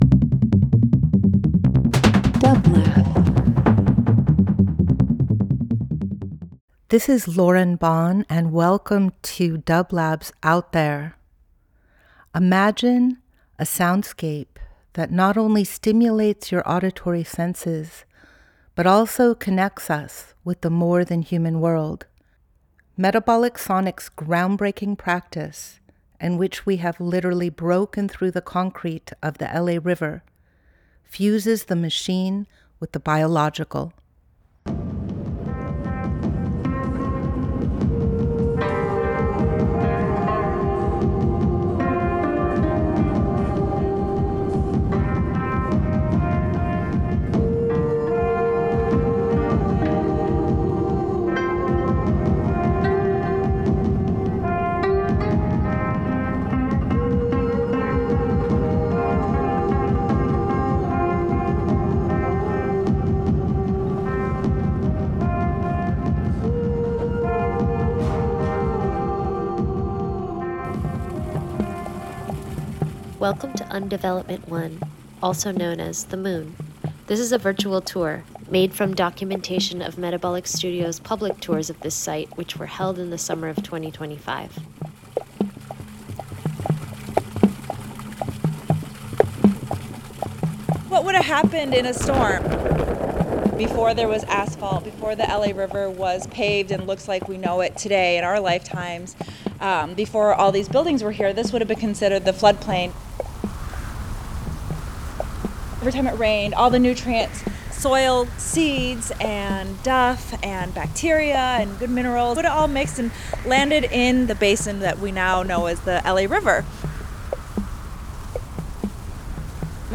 Each week we present a long-form field recording that will transport you through the power of sound.
Metabolic Studio Out There ~ a field recording program 07.31.25 Alternative Ambient Field Recording Fourth World Voyage with dublab and Metabolic into new worlds.